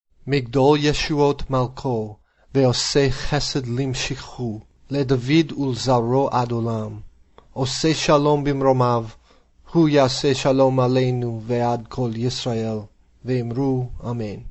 Hagadah Reading